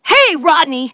synthetic-wakewords
ovos-tts-plugin-deepponies_Spike_en.wav